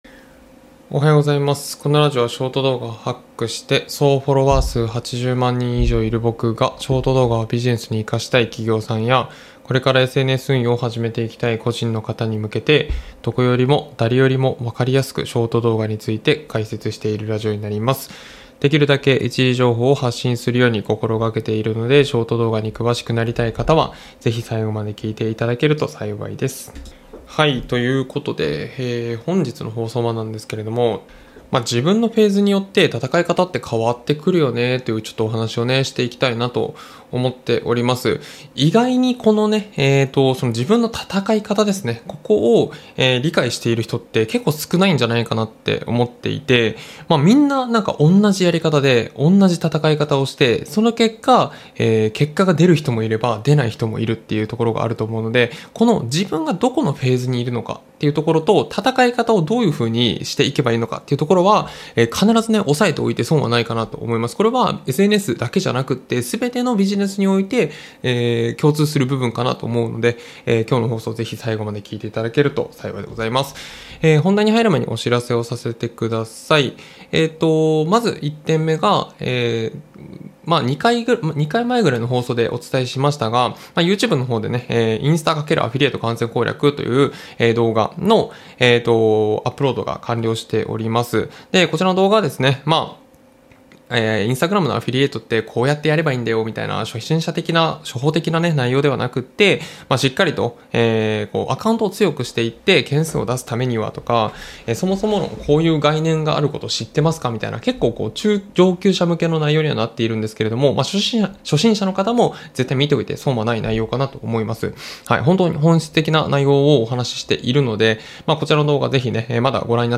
Audio Channels: 2 (stereo)